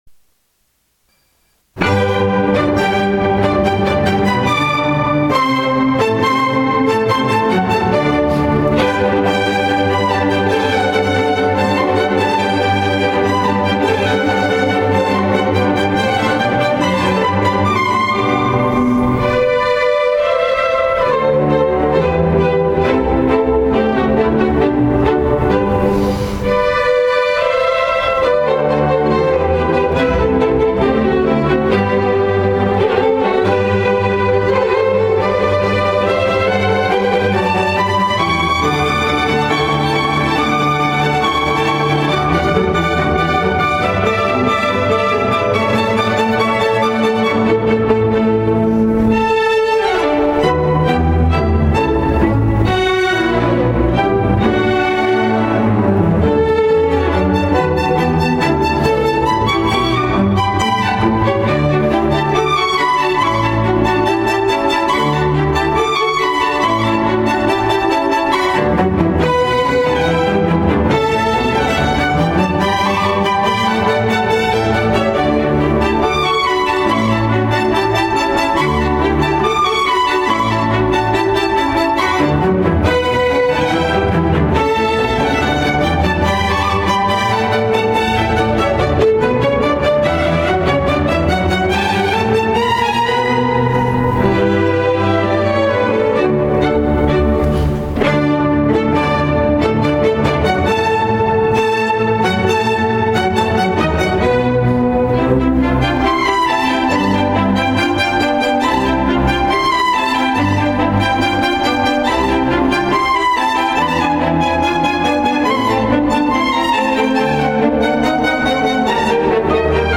74-3 「 ｱｲﾈ･ｸﾗｲﾈ･ﾅﾊﾄﾑｼﾞｰｸ ( 　〃　in埼玉･越谷) 」
( ﾓｰﾂｱﾙﾄ作曲　東京ﾌｨﾙ 4:04 ）